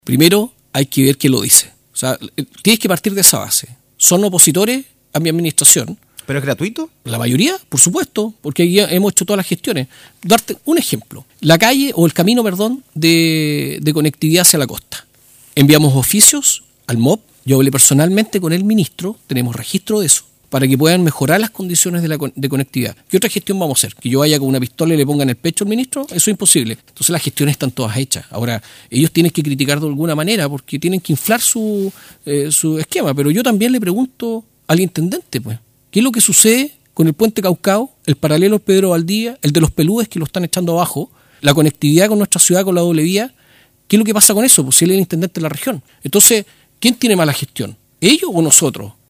En entrevista con Radio Bío Bío, el alcalde de Valdivia, se refirió a distintos temas de contingencia, proyectos locales y críticas a su gestión que han surgido desde la Nueva Mayoría y el Gobierno Regional.